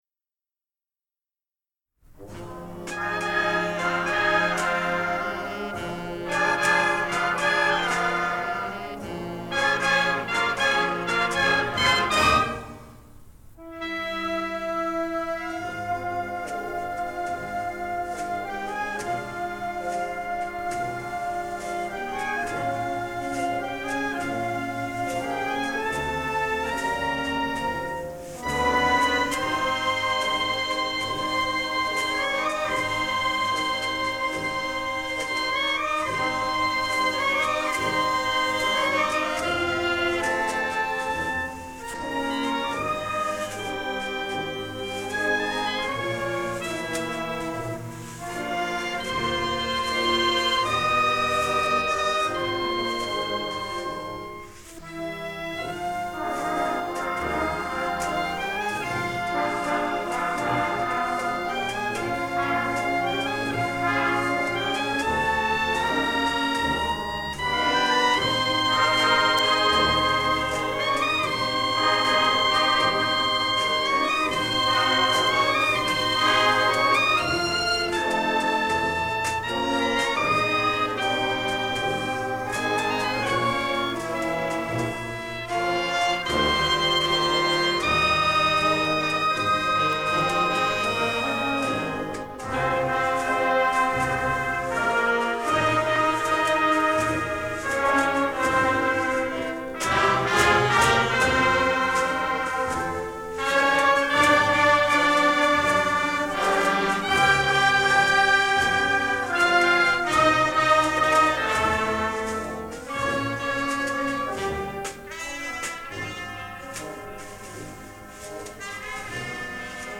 le disque de 1980 a été enregistré en mars 1979
avec son Revox A77 et deux micros à ruban Beyer M260